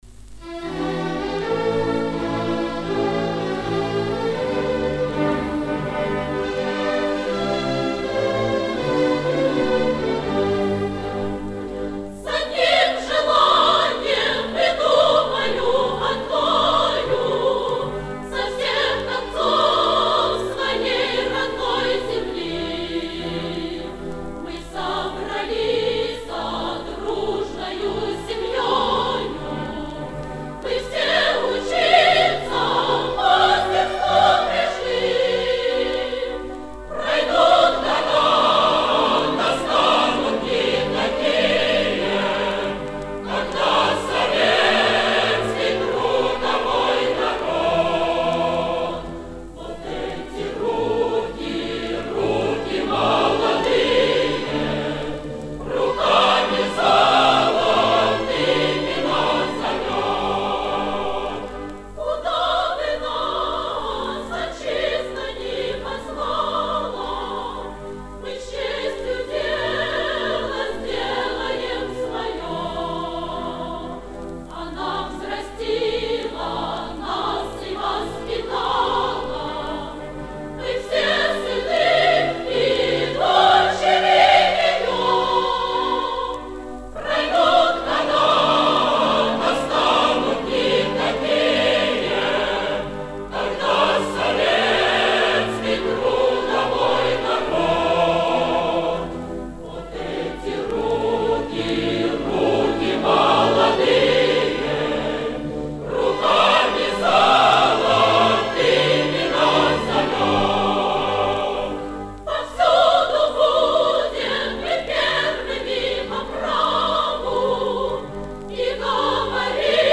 Более поздний вариант исполнения.